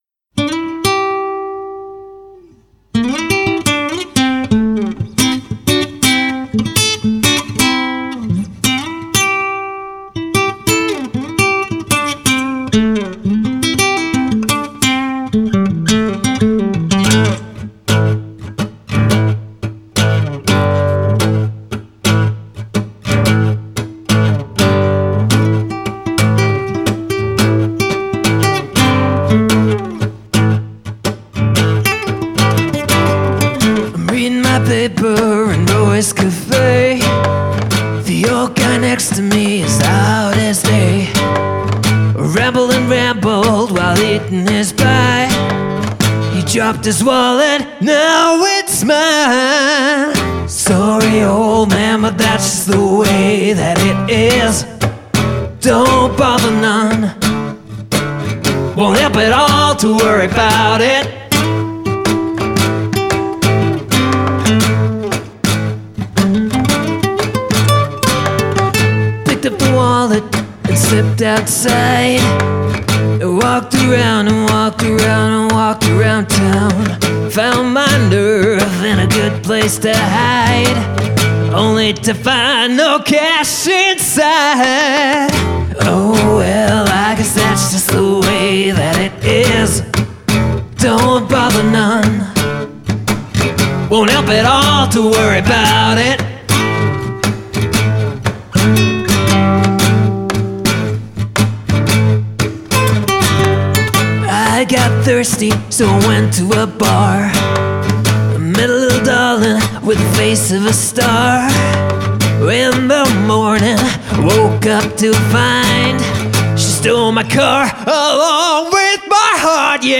Posted in Blues, Cover